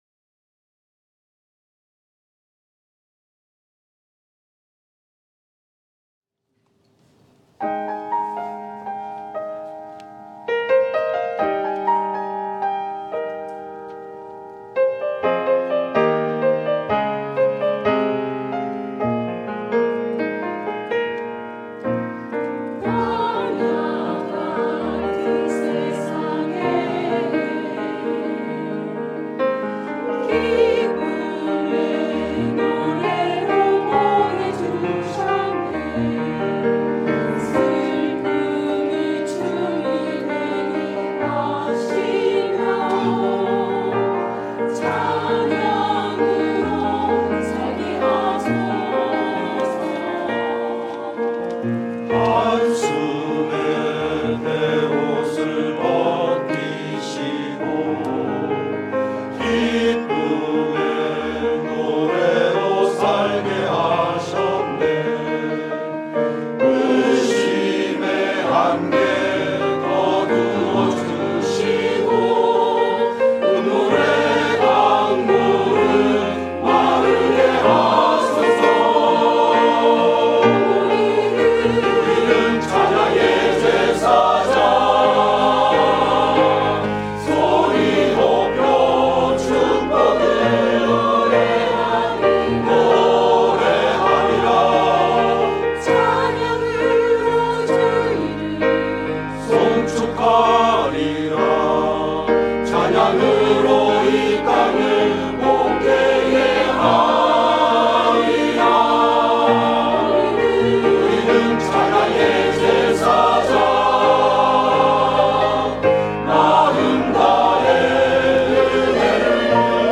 찬양의 제사장